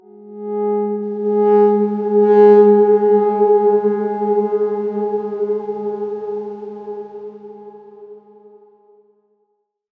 X_Darkswarm-G#3-mf.wav